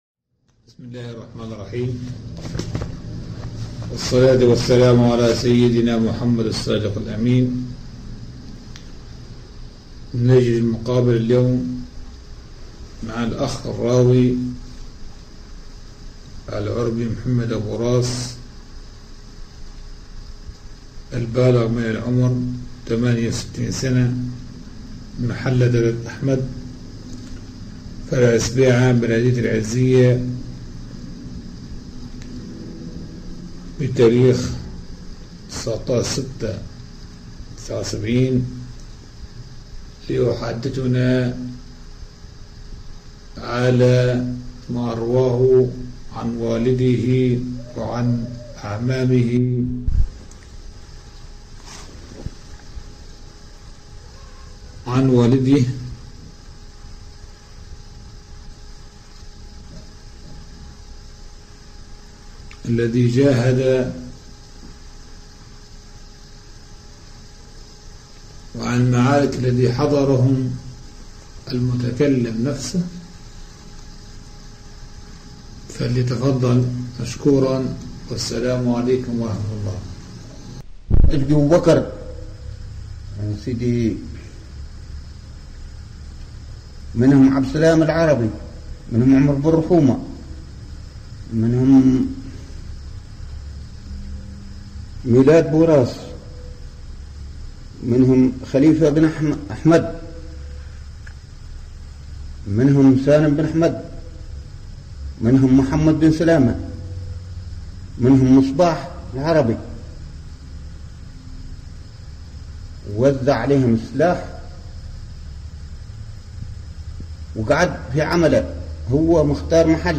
روايات شفوية معركة فندق الشيباني 17/11/1922م فندق الشيباني ، منطقة تلال جبلية تكثر فيها النباتات تبعد حوالي 10 كم من جنوب العزيزية، دارت بها معركة بين القوات الإيطالية بقيادة (بيللي) وقوات المجاهدين .